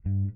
Bass Guitar Wilshire.wav